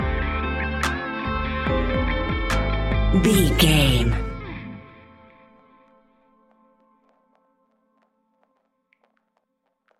Fast paced
Uplifting
Ionian/Major
A♭
hip hop